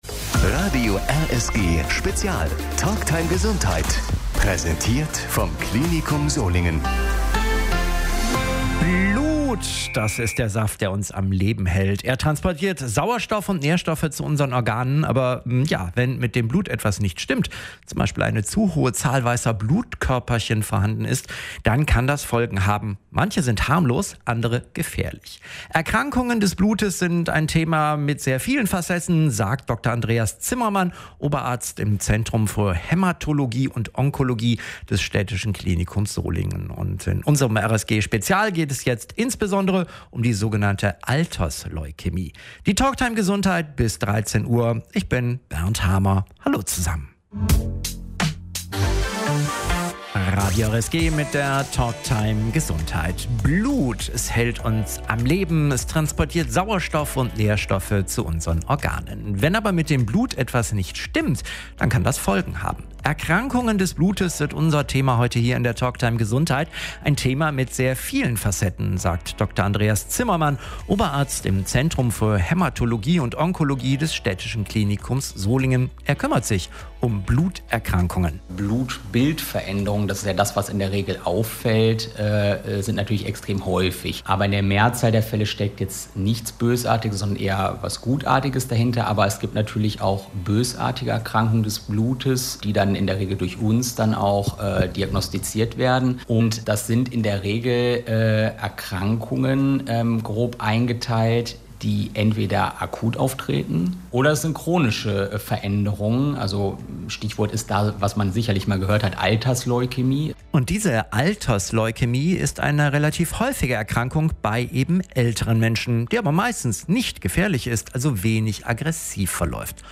Um Symptome und Therapie bei Altersleukäme ging es in der Radiosprechstunde am 25. Oktober.